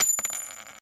coin_to_coin.ogg